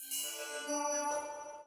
... c'est idiophone mais c'est pas ça !